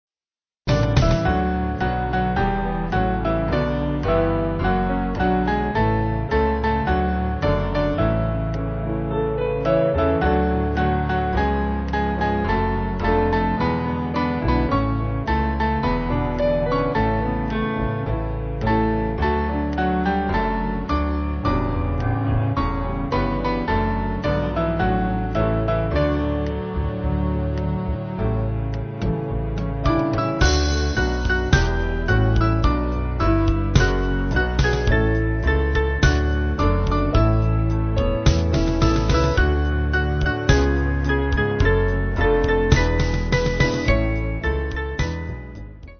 Small Band
(CM)   4/D-Eb